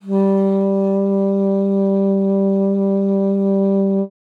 42e-sax03-g3.wav